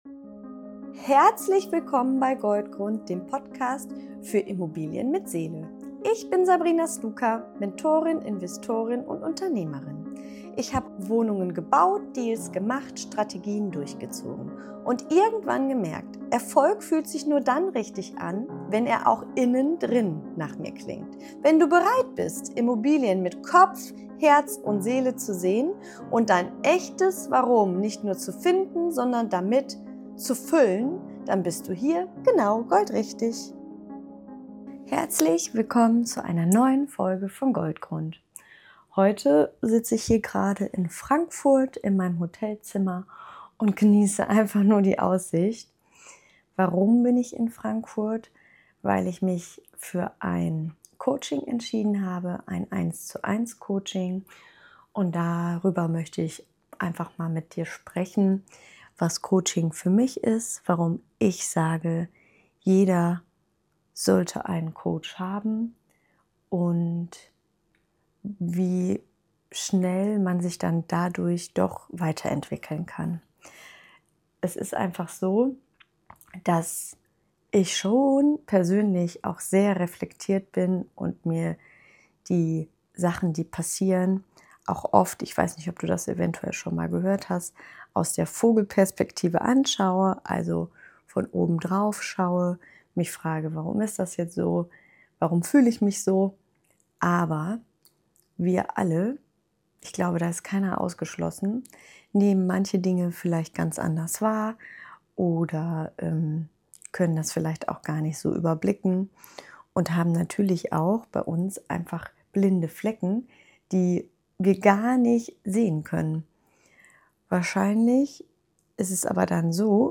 In dieser Folge nehme ich dich mit nach Frankfurt – direkt aus meinem Hotelzimmer – und teile mit dir, warum ich mich für ein neues 1:1 Coaching entschieden habe.